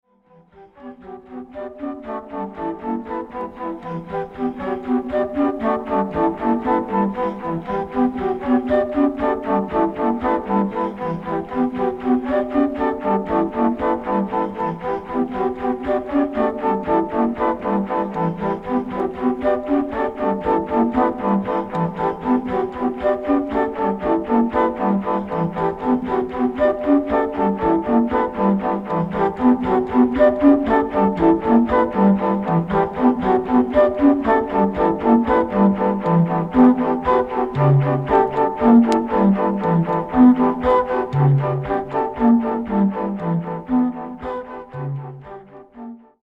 フルート、声、リズムマシーンの他、鳥の鳴き声などの自然音を交えた多重録音による作品。
キーワード：ミニマル　即興　フルート